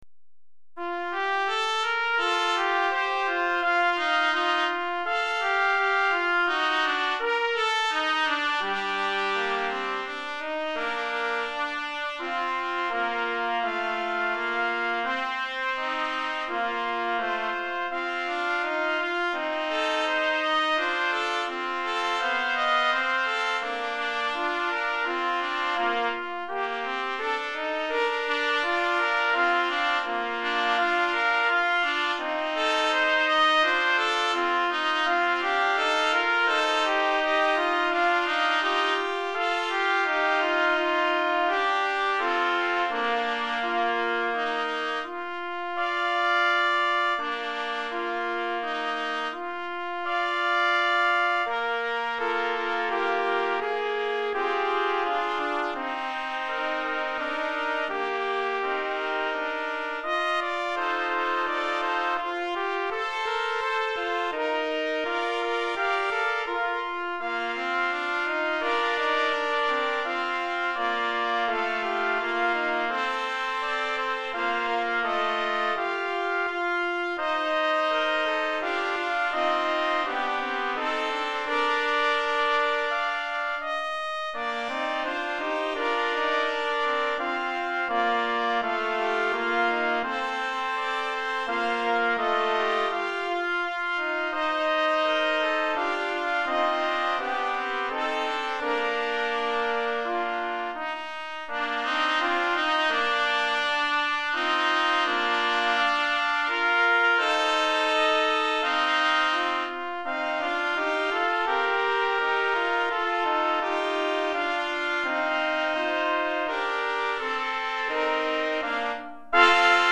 4 Trompettes